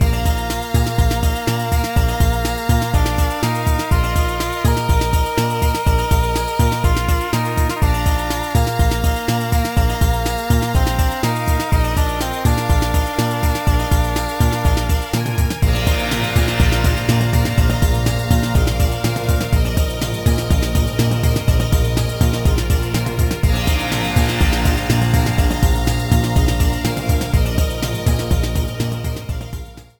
Clipped to 30 seconds with fade-out.